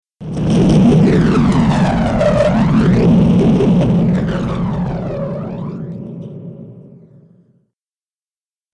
دانلود صدای موشک 11 از ساعد نیوز با لینک مستقیم و کیفیت بالا
جلوه های صوتی
برچسب: دانلود آهنگ های افکت صوتی حمل و نقل دانلود آلبوم صدای انواع موشک از افکت صوتی حمل و نقل